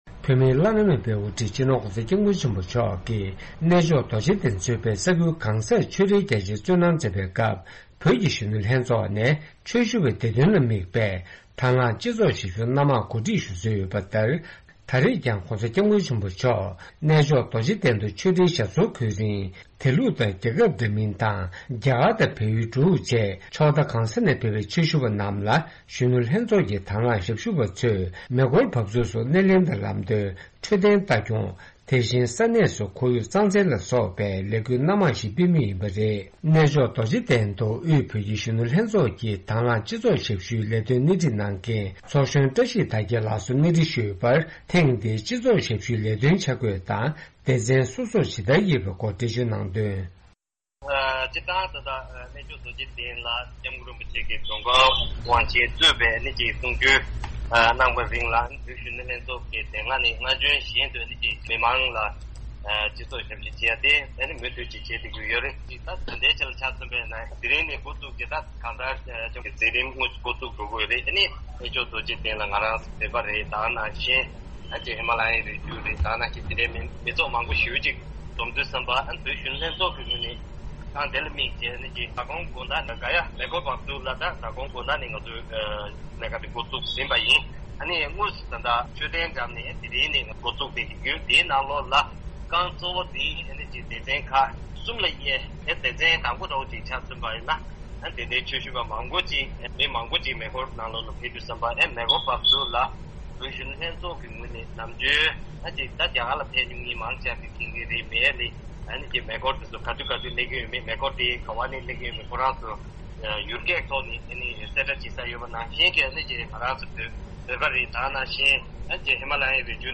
གནས་འདྲི་ཕྱོགས་སྒྲིག་ཞུས་པ་གསན་རོགས་གནང་།